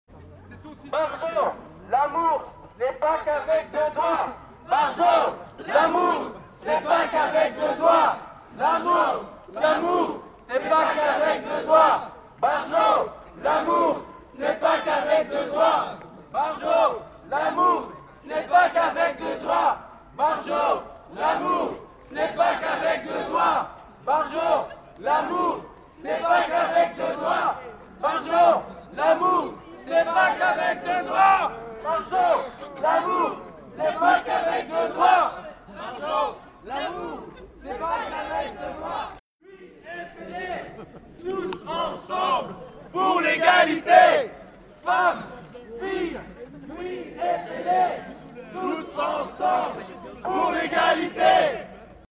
Ceci n’a pas fait reculer les personnes sur place qui se sont bien fait entendre, et ont pu apercevoir à 200m de là, la Frijide qui pavanait sous les huées.
slogans-2.mp3